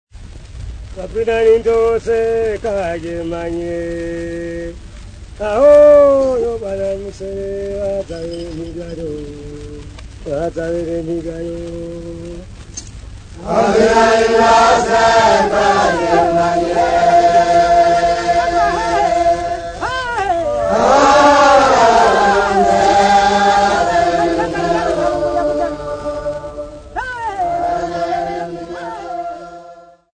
Dance music
Field recordings
Africa Tanzania Dodoma f-sa
Nindo praise song accompanied by stamping, Ndwala bells, horn and whistles